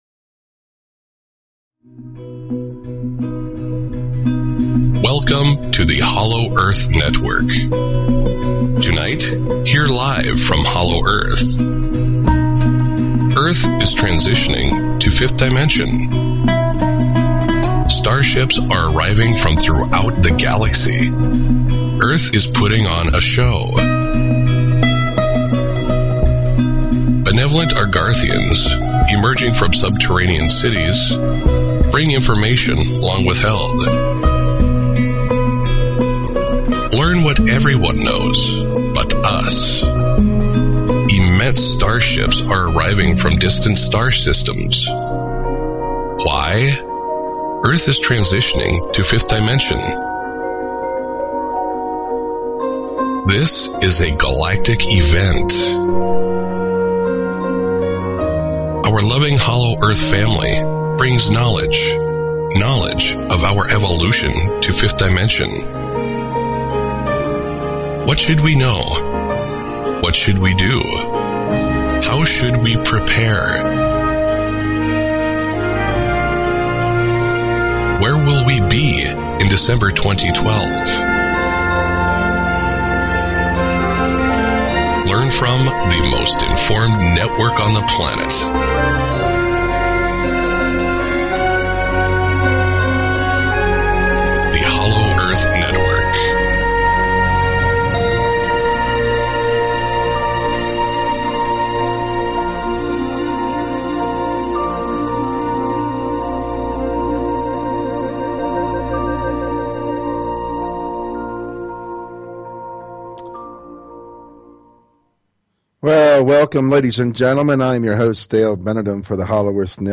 Talk Show Episode, Audio Podcast, Hollow_Earth_Network and Courtesy of BBS Radio on , show guests , about , categorized as